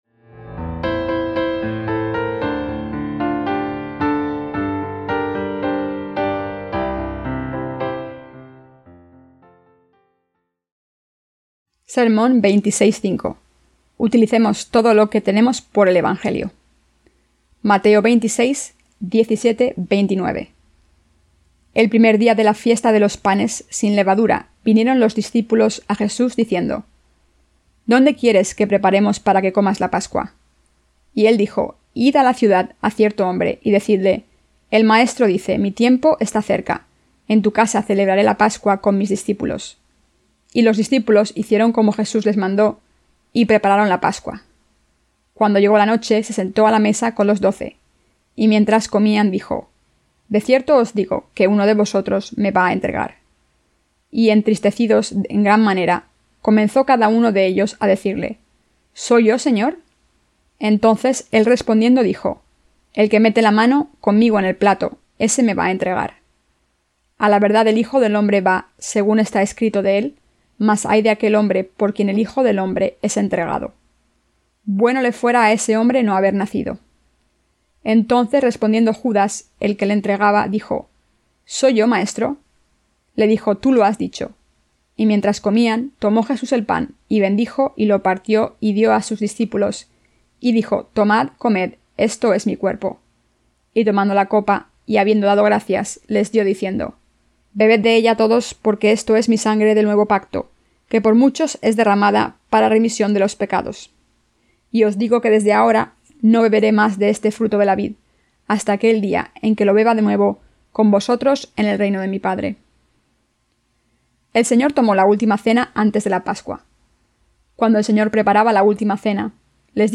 SERMONES SOBRE EL EVANGELIO DE MATEO (VI)-¿A QUIÉN SE LE PRESENTA LA MEJOR VIDA?